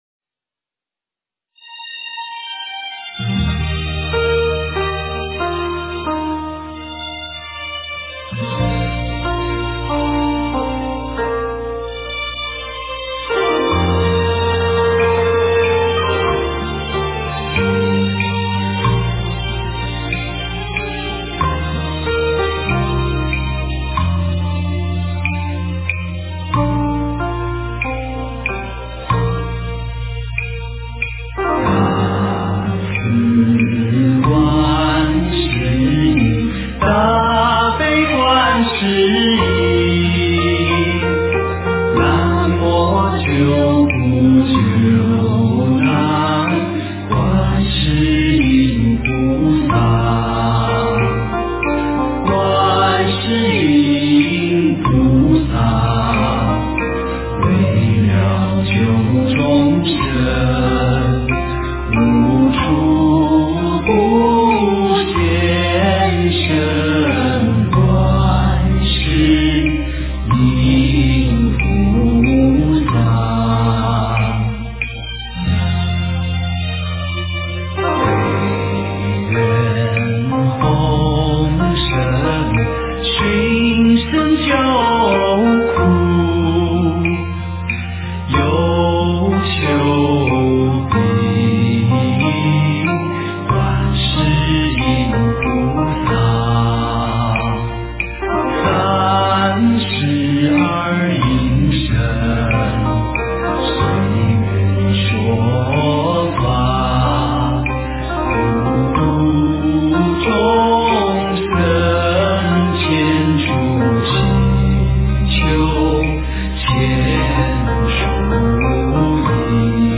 观音行愿曲--佛教音乐